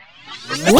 VEC3 Reverse FX
VEC3 FX Reverse 18.wav